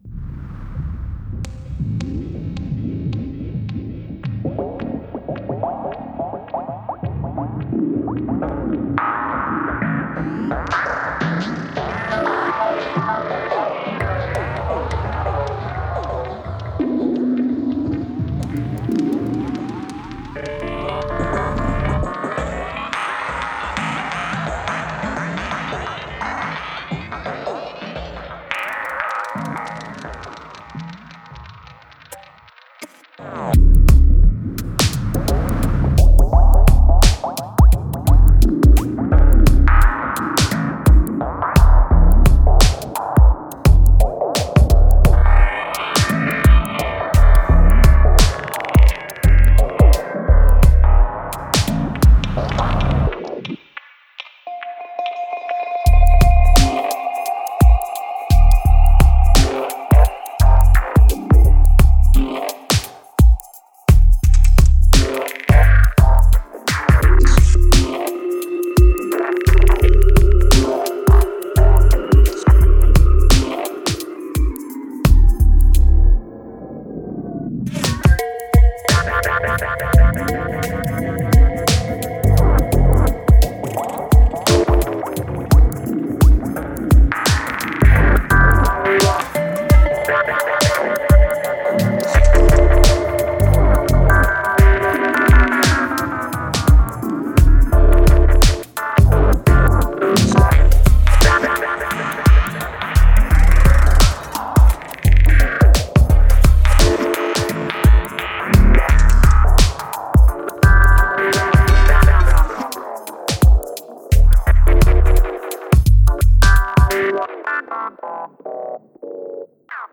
Genre: Ambient, IDM, Chillout.